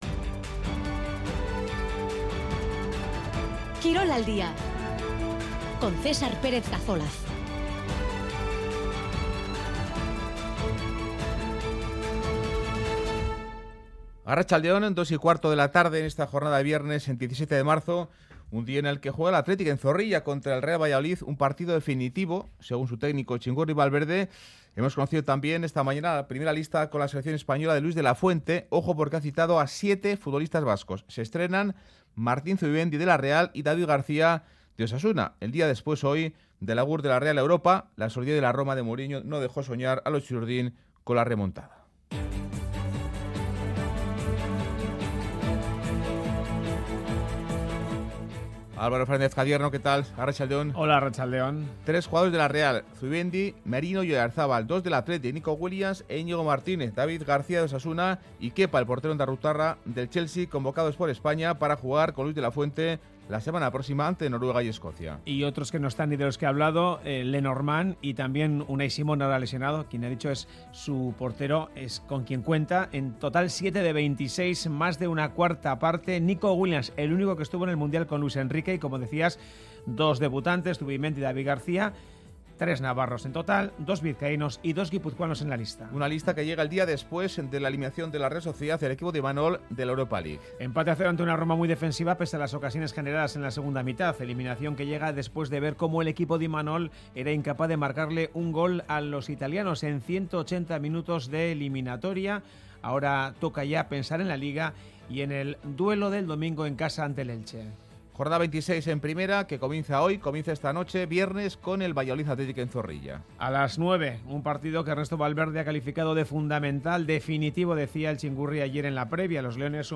Informativo de actualidad deportiva